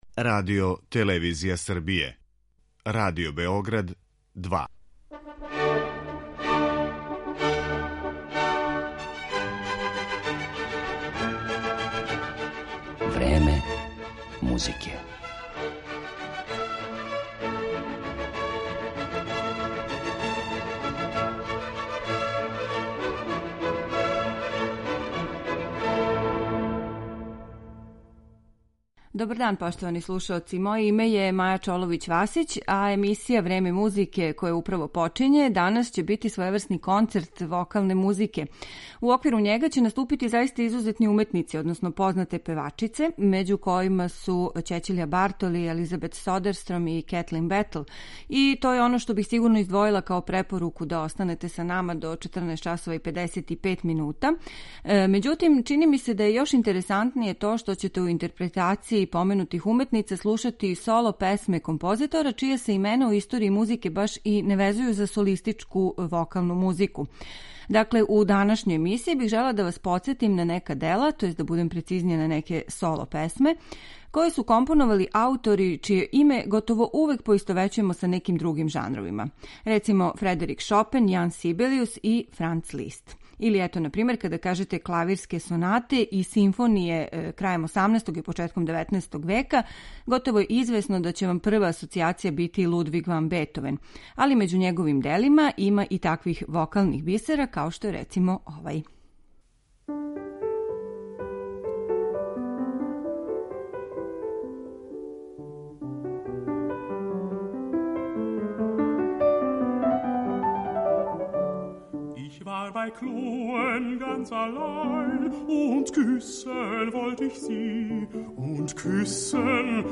Соло песма у опусима познатих композитора клавирске и симфонијске музике
слушећете у извођењу Ћећилије Бартоли, Елизабет Содерстром, Кетлин Бетл и других врхунских светских певачица.